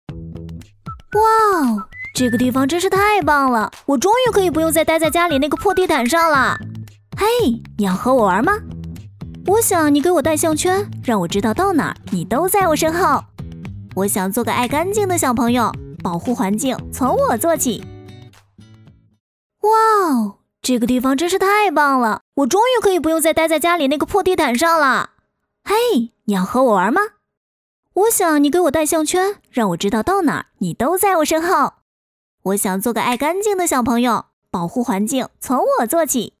女3号（甜美、知性）
女3-文明“修勾” 活泼可爱
女3-文明“修勾”活泼可爱.mp3.mp3